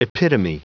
Prononciation du mot epitome en anglais (fichier audio)
Prononciation du mot : epitome